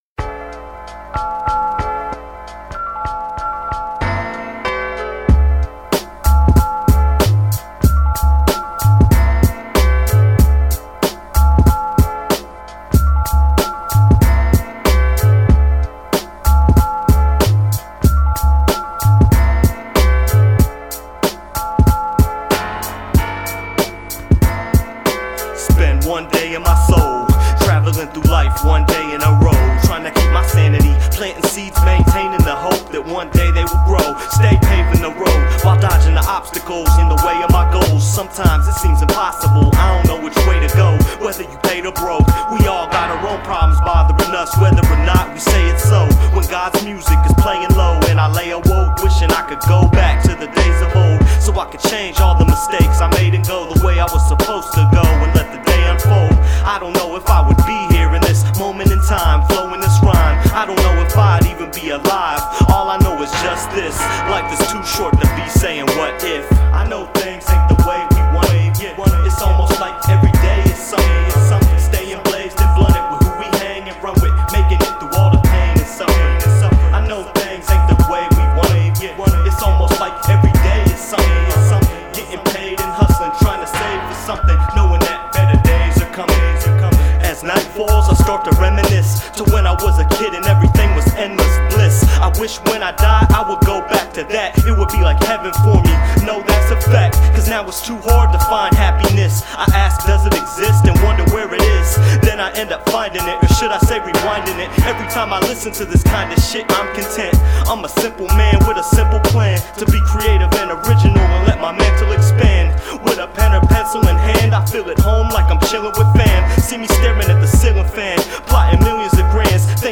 Underground Hip Hop